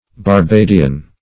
Barbadian \Bar*ba"di*an\, a.